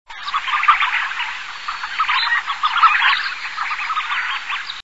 Fuligule morillon
Femelle morillon, aythya fuligula